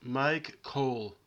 "Cole"; born February 1, 1945) is a Canadian politician who has served as deputy mayor of Toronto since 2023, representing North York.